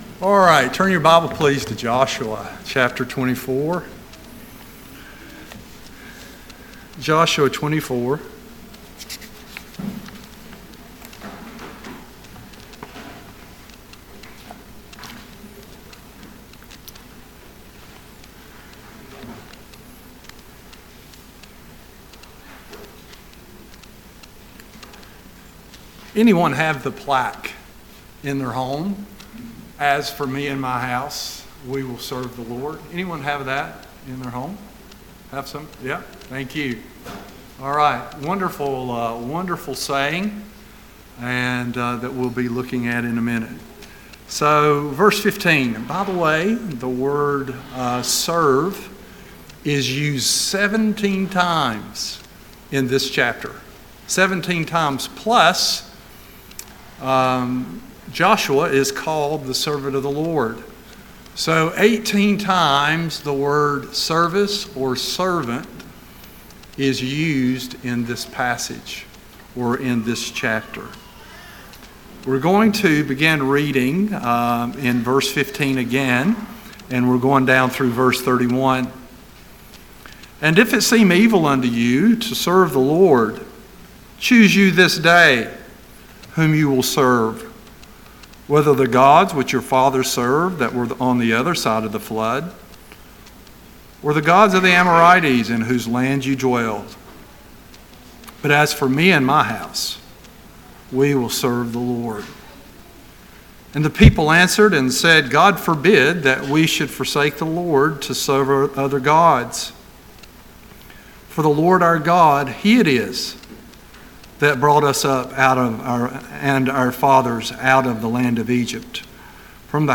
Service Type: Sunday AM